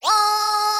LoudWailLoop.wav